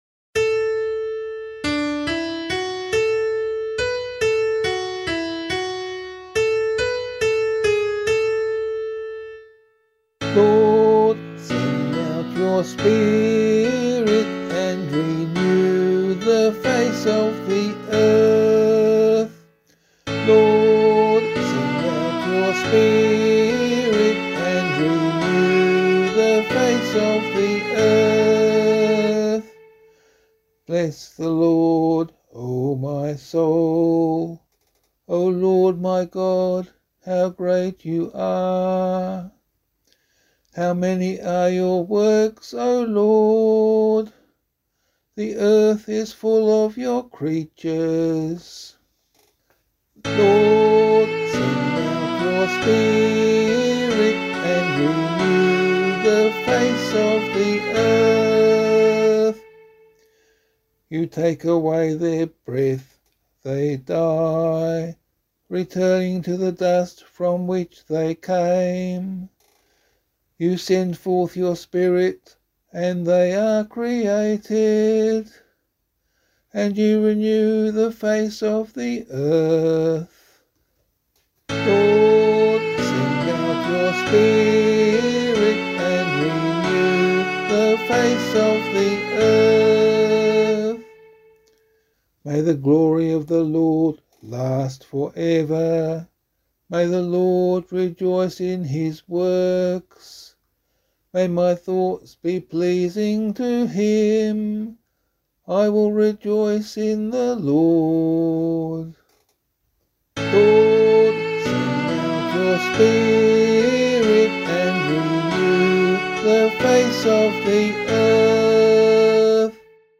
032 Pentecost Day Psalm [Abbey - LiturgyShare + Meinrad 8] - vocal.mp3